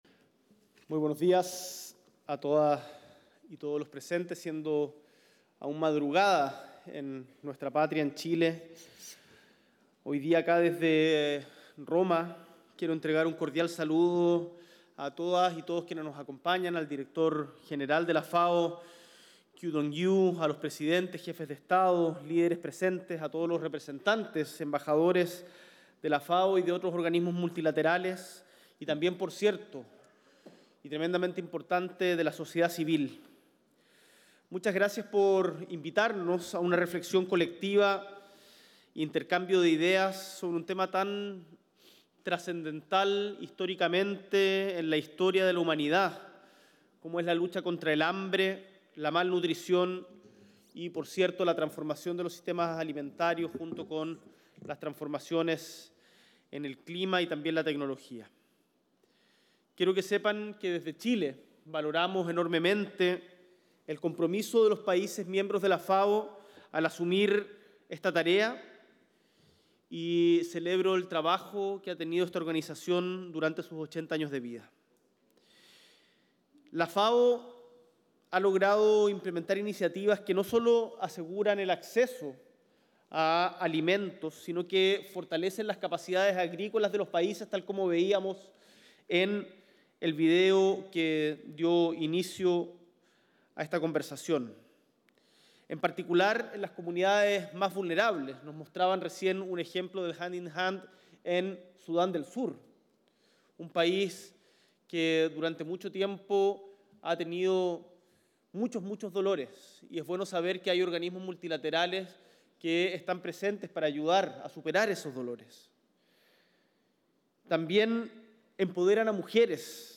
S.E. el Presidente de la República, Gabriel Boric Font, encabeza la ceremonia inaugural del Foro de Inversión de la Iniciativa Mano de la Mano de la Organización de las Naciones Unidas para la Alimentación y la Agricultura
Discurso